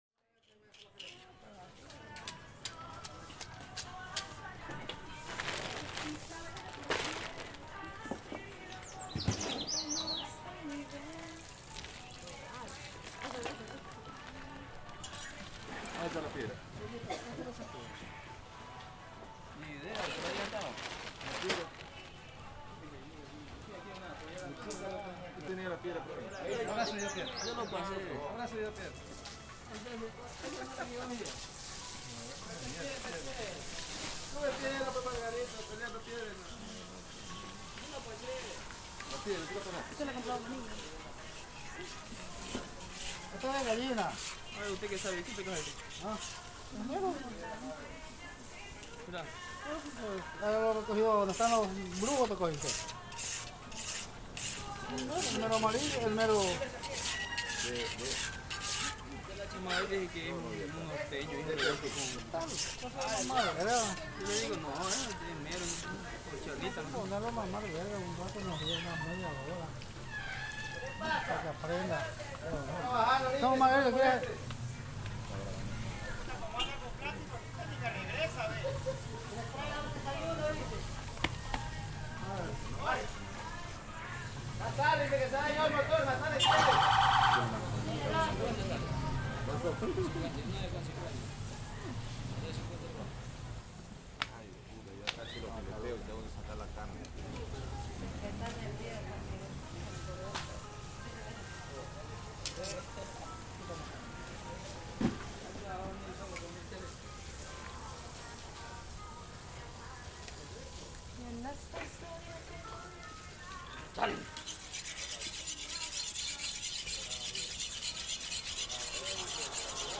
The field recording I chose from the Fisherman’s Wharf in Santa Cruz in the Galapagos Islands
Listening to the recording, I was mesmerised by the sound of the ‘knives sliding on whetstones’, as well as the daily conversations and the flowing of sounds into each other.